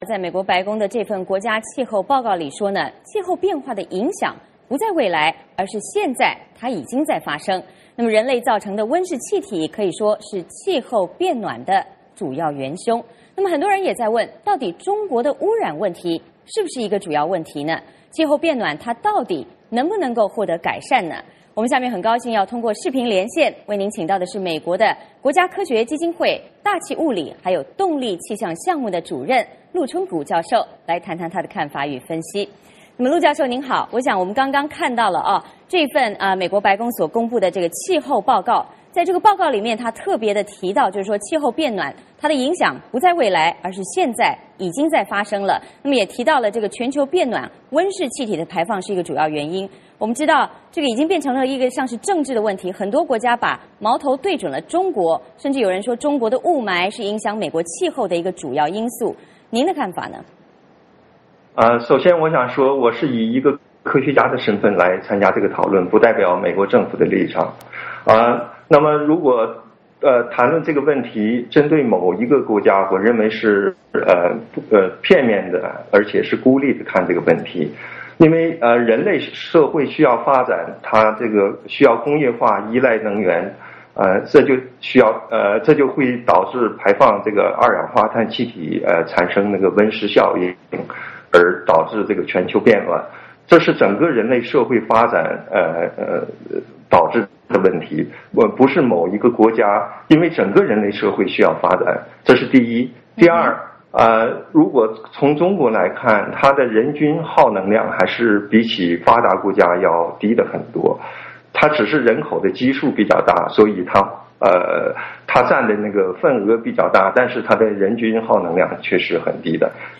我们通过视频连线